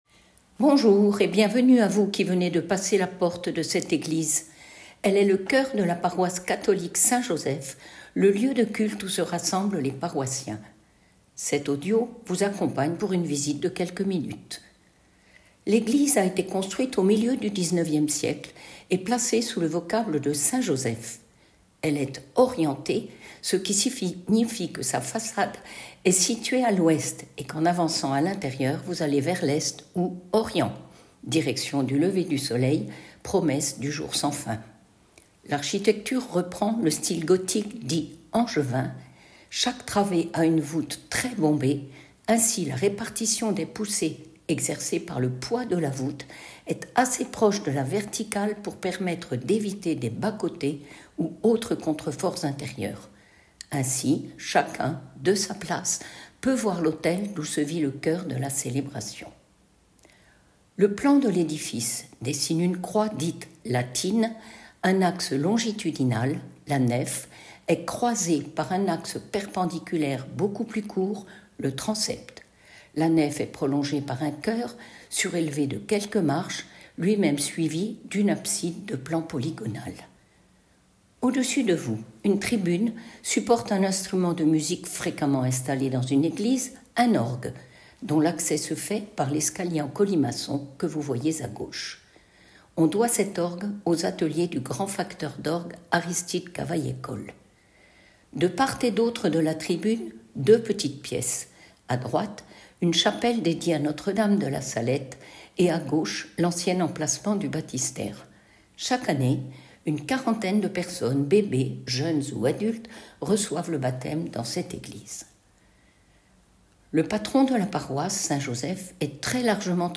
Petite visite audio de l’église en quelques minutes